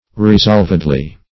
Search Result for " resolvedly" : The Collaborative International Dictionary of English v.0.48: Resolvedly \Re*solv"ed*ly\ (r?z?lv"?d-l?), adv. 1.